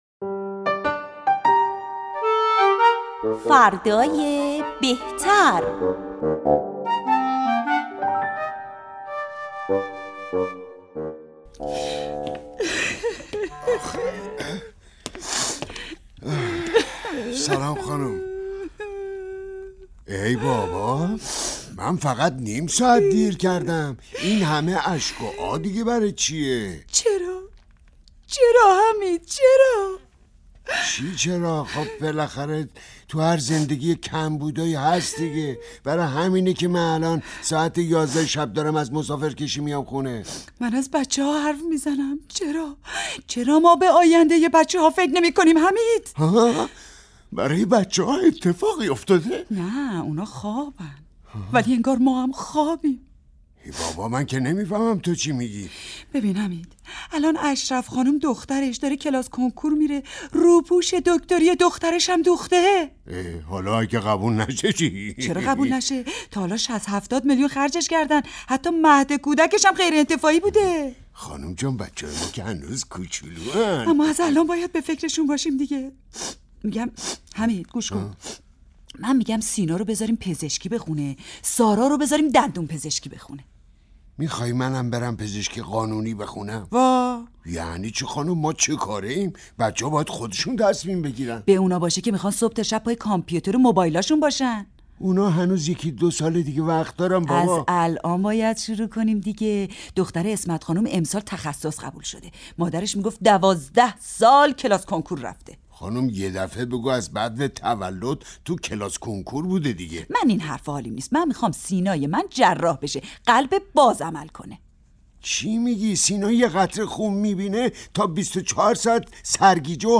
به گزارش خبرنگار مهر، نمایش رادیویی «فردای بهتر» درباره زن و شوهری است که از فرزندانشان و آینده آنها حرف می زنند و زن با اشک می گوید که شوهرش به فکر آینده آنها نیست و بعد توضیح می دهد که چگونه باید فرزندان را به کلاس های آموزشی و کنکور گذاشت.
- (صدای گریه می آید)